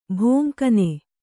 ♪ bhōnkane